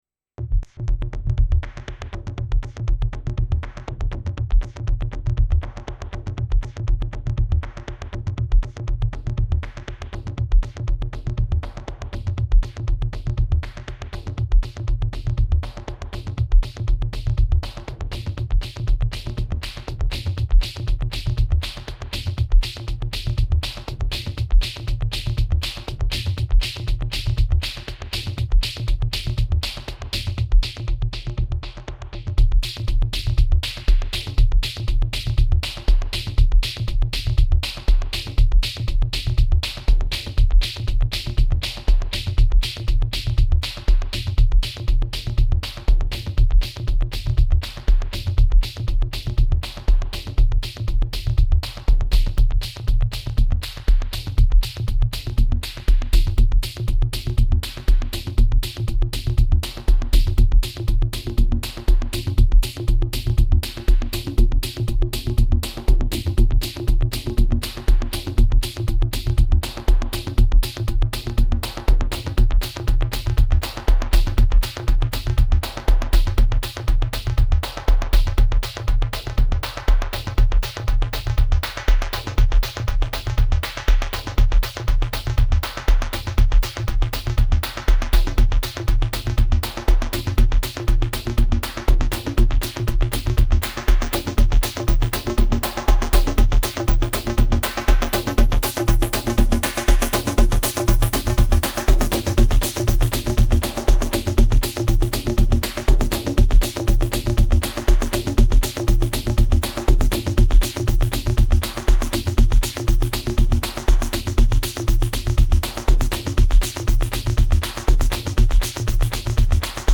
My first try is maximalist machine gun mess. 1 pattern, 7 tracks.